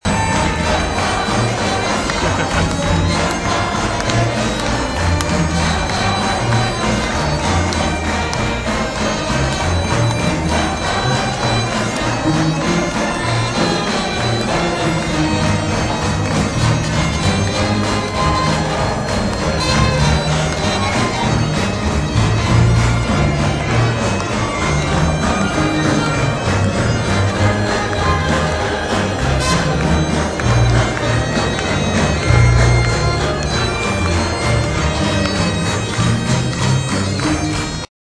Scottish Pipe Jazz From Bute
14336-scottish-pipe-jazz-from-bute.mp3